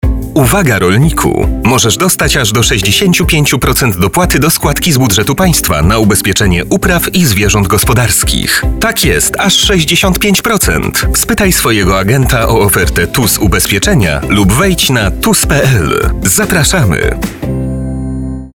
Lokalna kampania radiowa Ubezpieczenia upraw
Spot o takiej samej treści emitowany jest od 26 września do 25 października w Radio Kaszëbë.